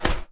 shield-hit-6.ogg